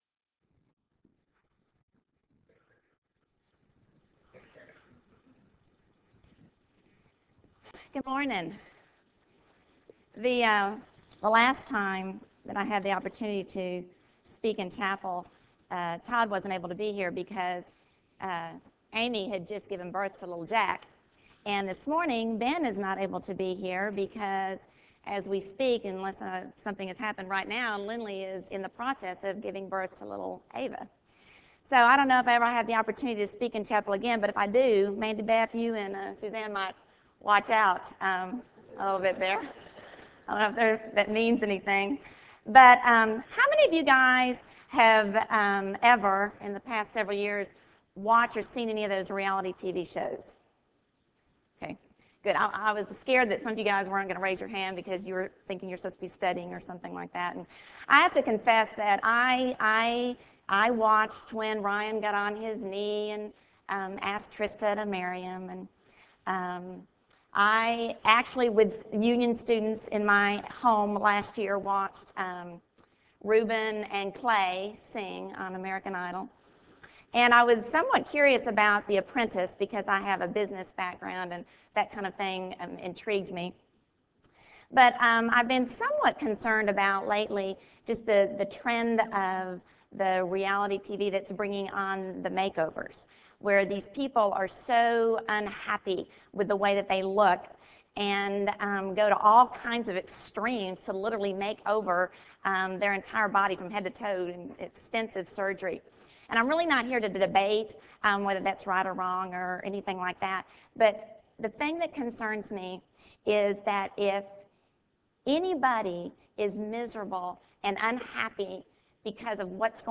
Chapel Service